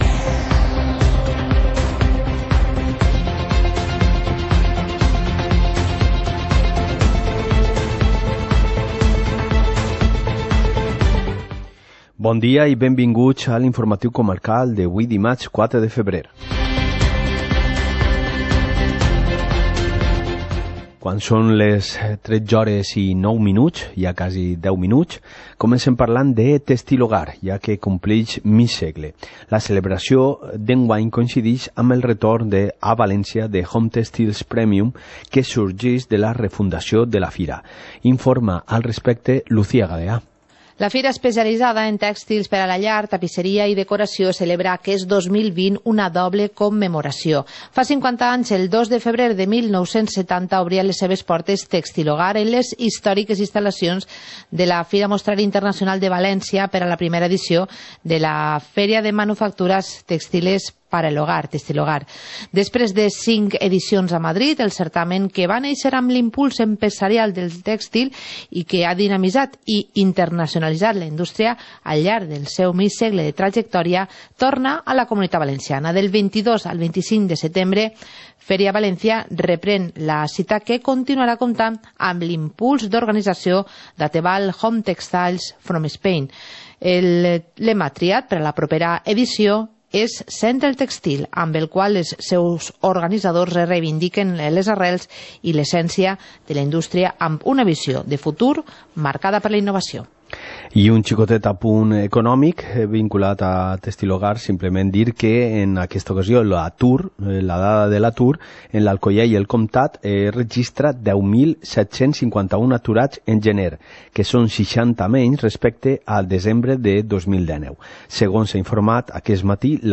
Informativo comarcal - martes, 04 de febrero de 2020